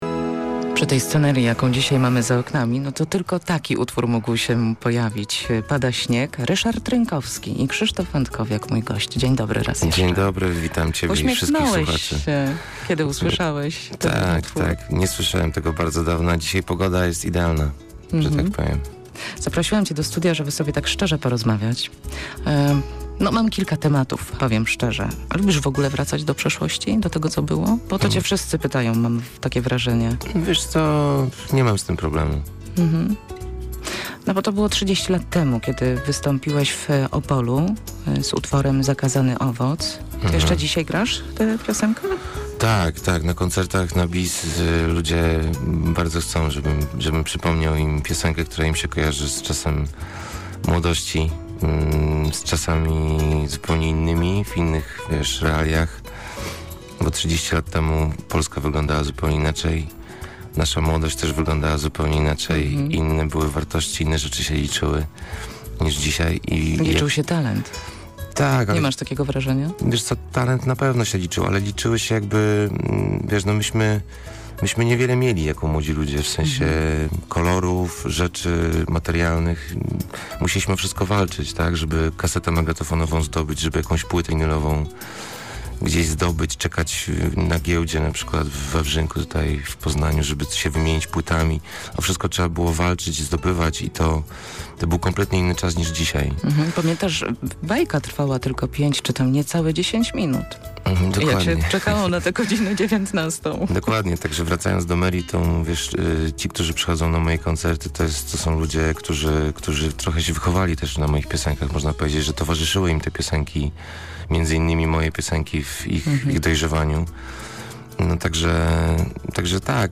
W wywiadzie